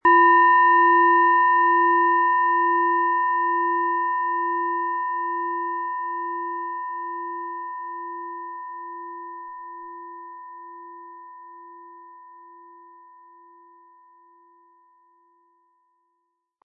Diese von  Hand getriebene Planetentonschale Platonisches Jahr wurde in einem kleinen indischen Dorf gefertigt.
Ein unpersönlicher Ton.
MaterialBronze